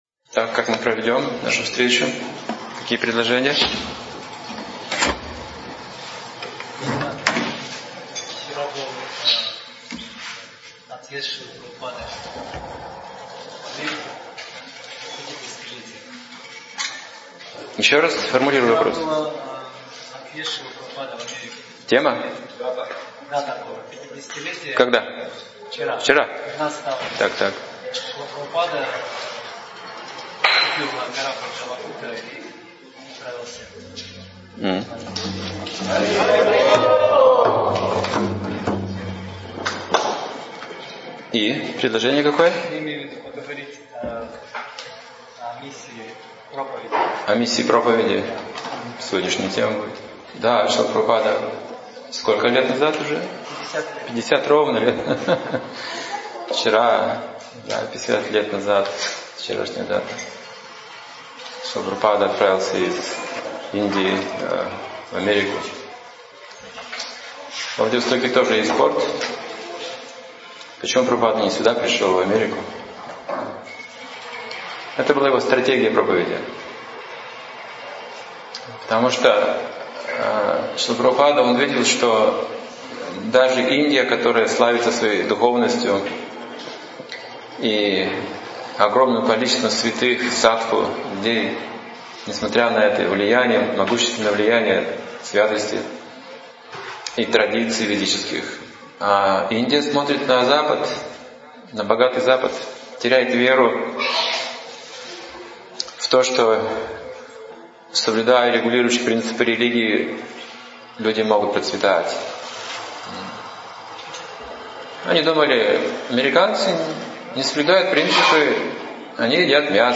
Замечательная лекция о подвиге великого сподвижника, ачарьи-основателя Международного Общества Сознания Кришны, Шрилы Прабхупады, о его миссии и наследии.